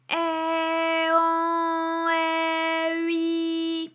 ALYS-DB-001-FRA - First, previously private, UTAU French vocal library of ALYS
ai_an_ai_ui.wav